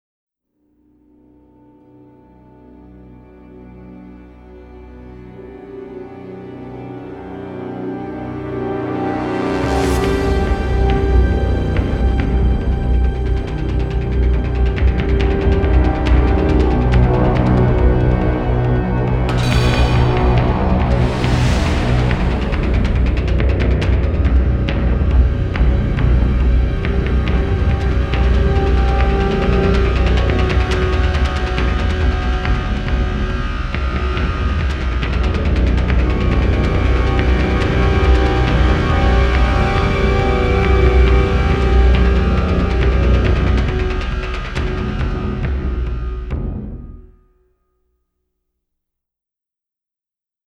hip-hop
to English electronica
synthesizers, orchestra and choir
an unusually subtle yet powerful and dramatic score.